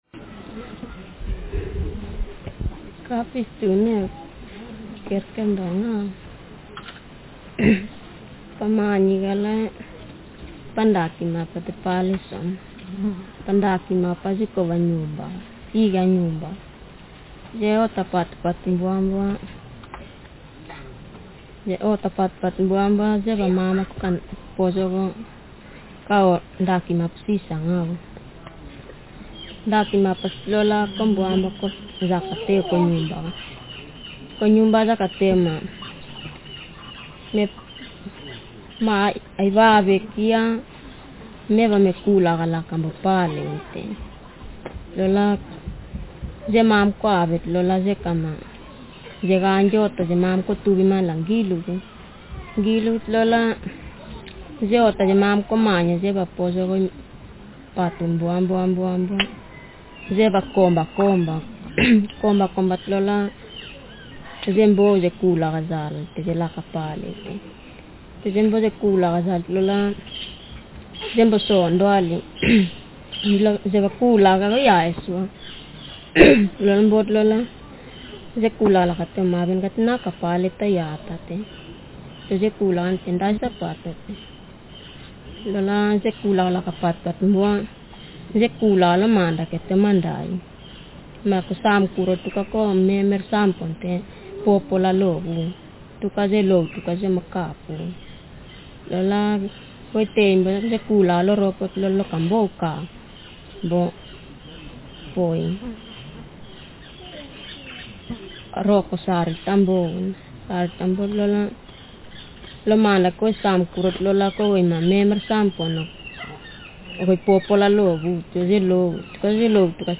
Speaker sexf
Text genretraditional narrative